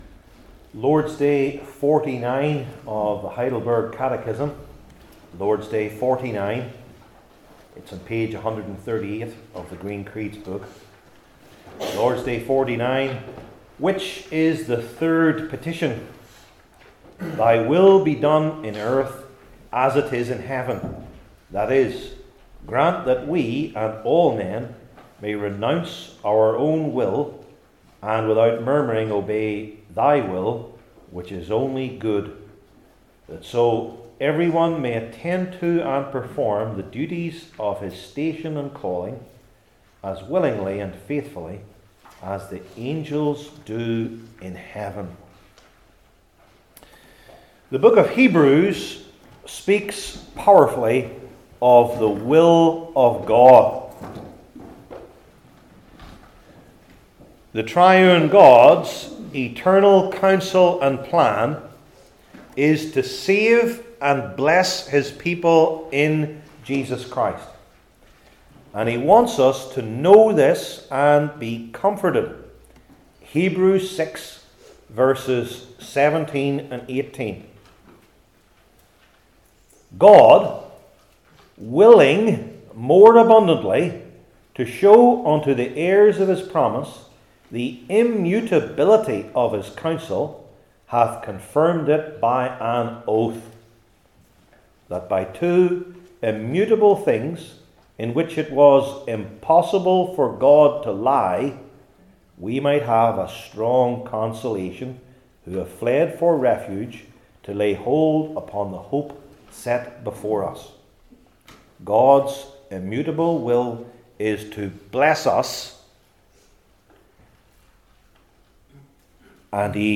Heidelberg Catechism Sermons I. Among the Brethren II.